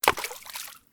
missed.wav